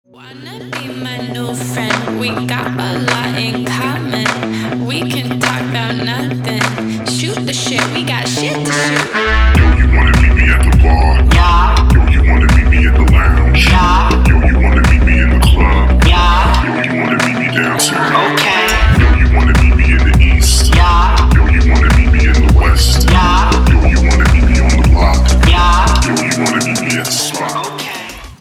dance
Electronic
EDM